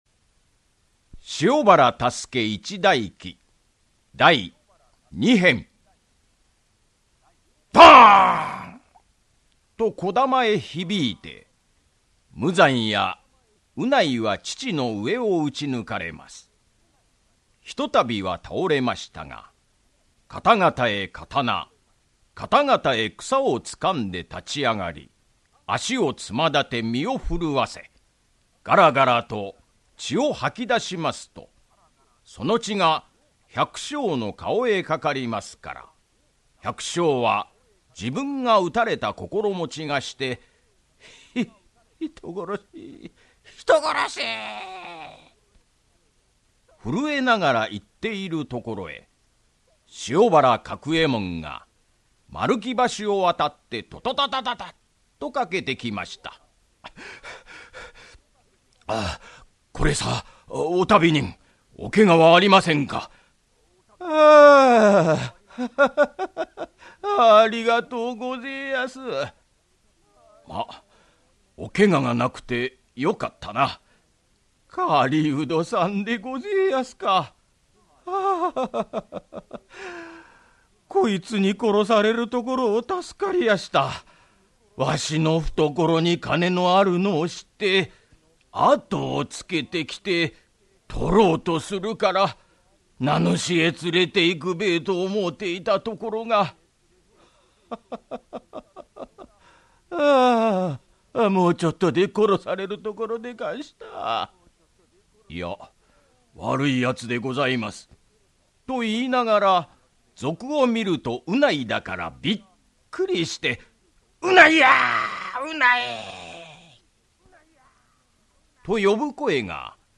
[オーディオブック] 塩原多助一代記-第二・三編-